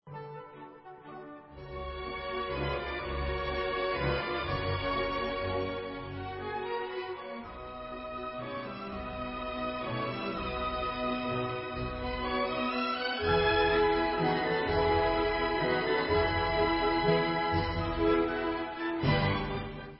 F dur (Allegro) /Skočná